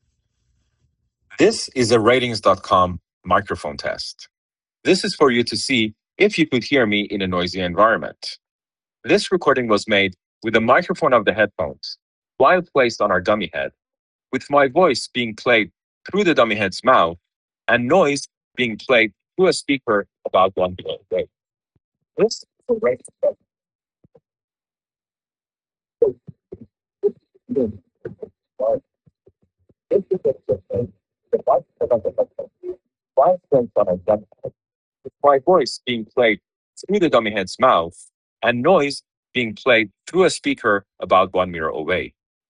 Speech + Subway Noise Audio Sample
With fluctuating background sounds like passing trains, your voice gets more or less drowned out.
The noise gate seems to work better when you pair the buds to an iPhone 16e.